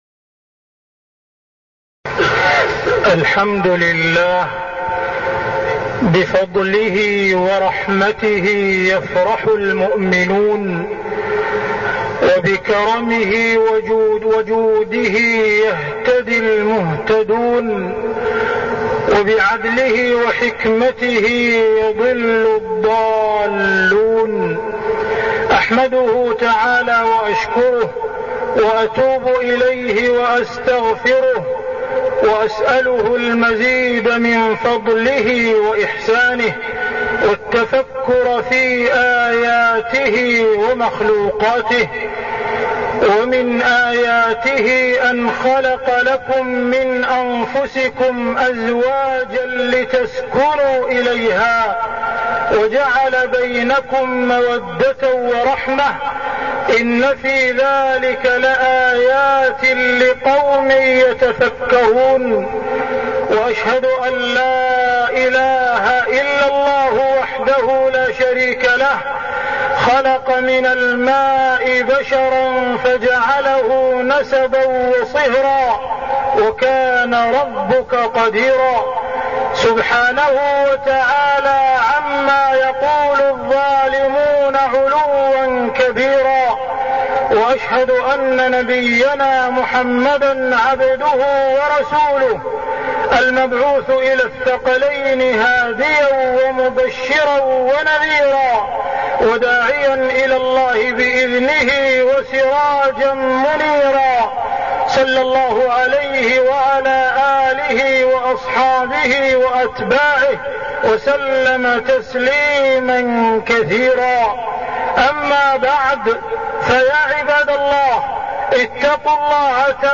تاريخ النشر ٢٤ محرم ١٤١٣ هـ المكان: المسجد الحرام الشيخ: معالي الشيخ أ.د. عبدالرحمن بن عبدالعزيز السديس معالي الشيخ أ.د. عبدالرحمن بن عبدالعزيز السديس منكرات الأفراح